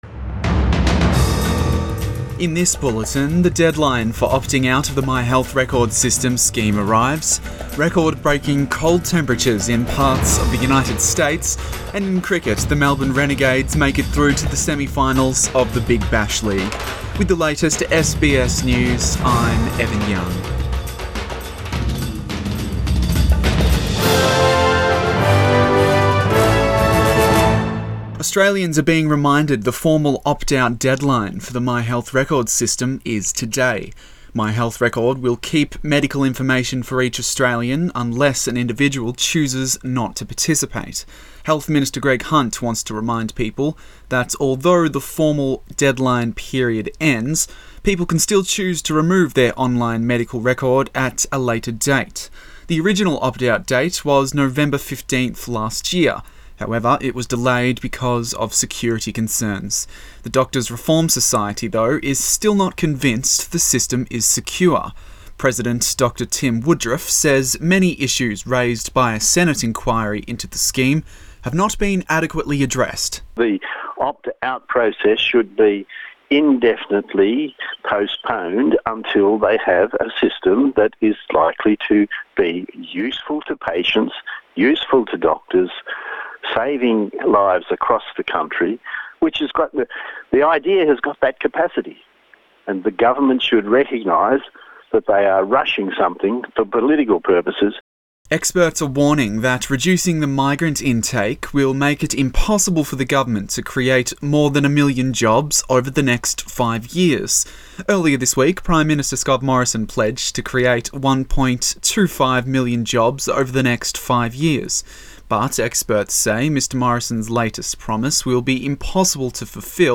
AM bulletin 31 January